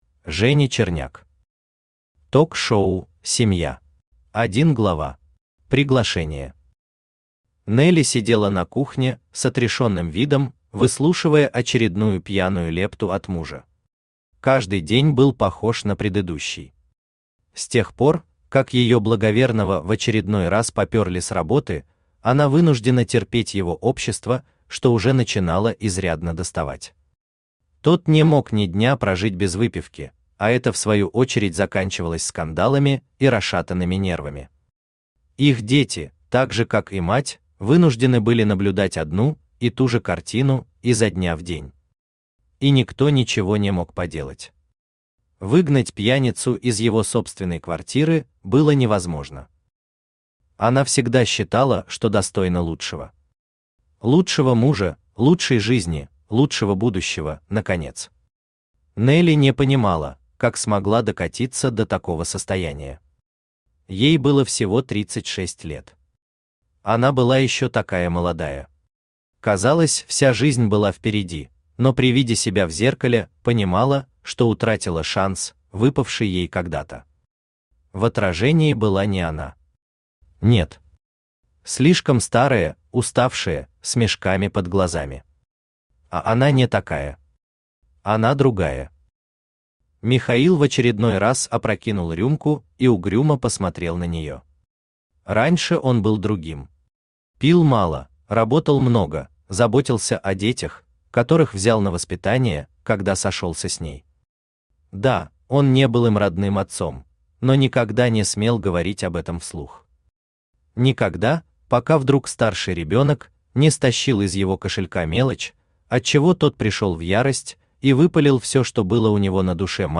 Aудиокнига Ток-шоу «Семья» Автор Женя Черняк Читает аудиокнигу Авточтец ЛитРес.